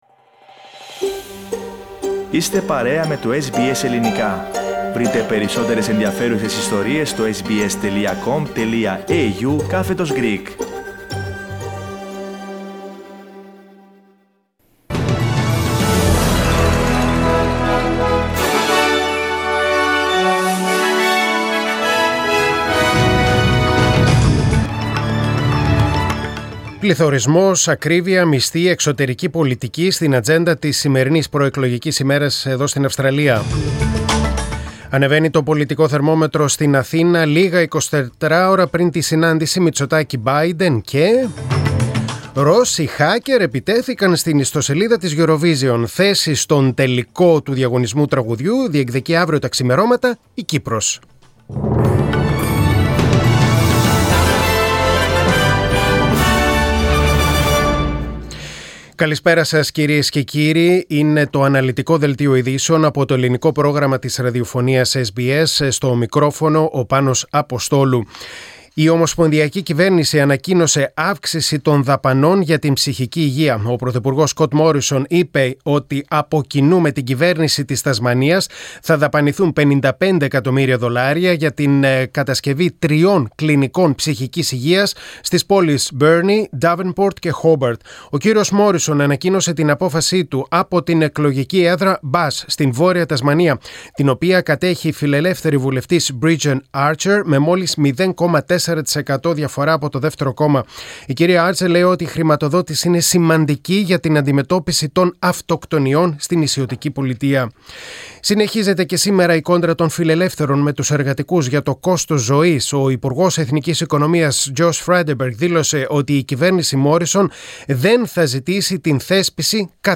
Δελτίο Ειδήσεων: Πέμπτη 12.5.2022